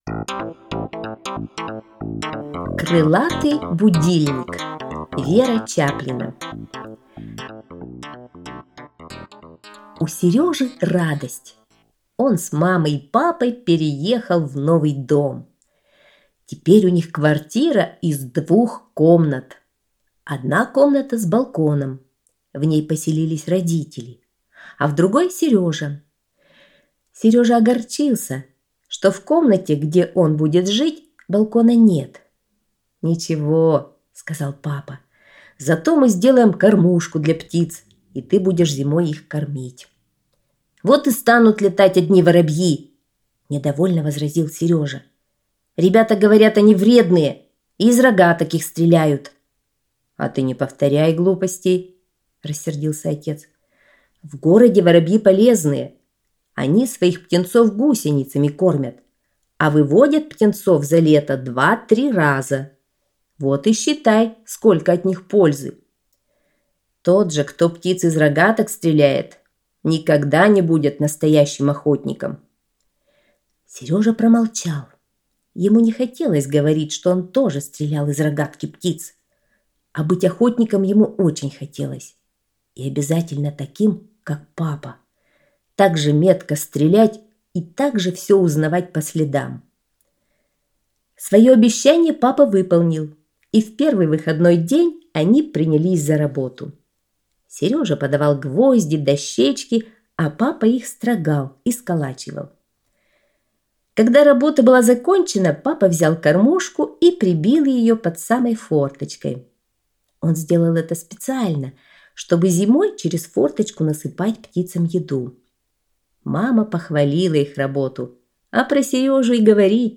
Аудиорассказ «Крылатый будильник»